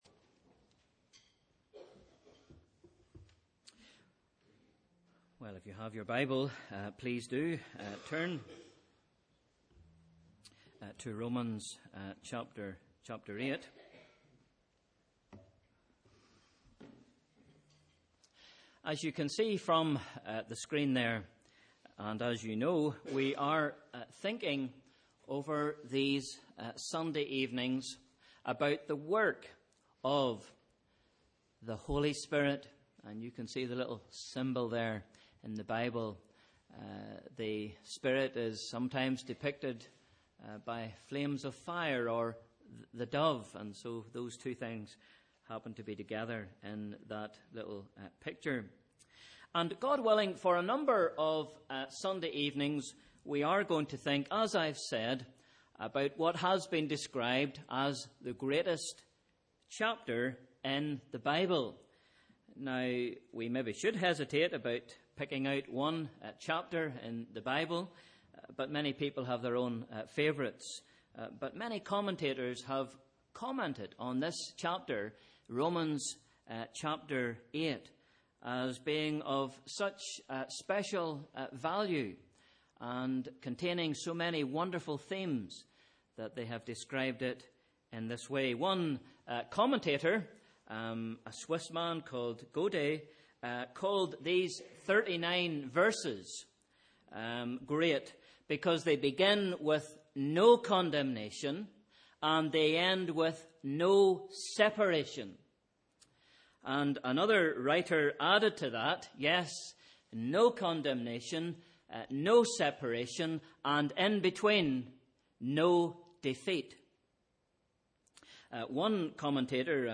Sunday 7th February 2016 – Evening Service